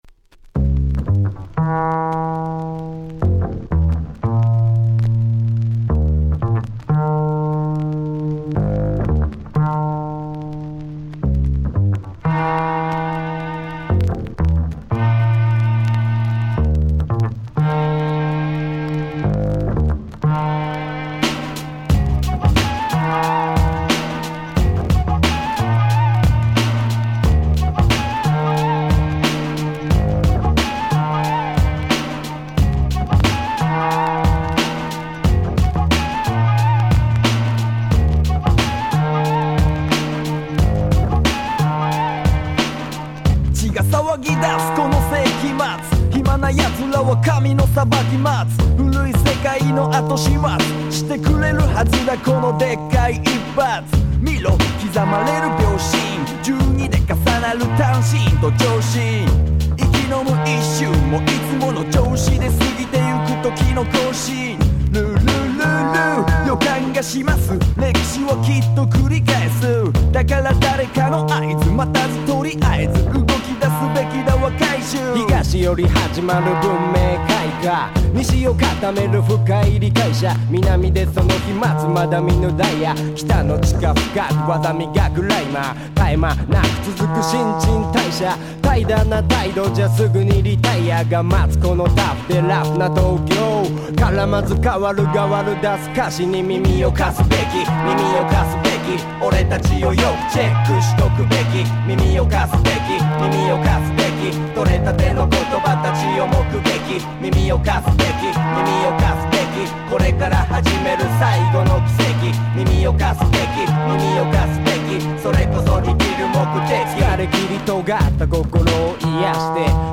96' Japanese Hip Hop Super Classics !!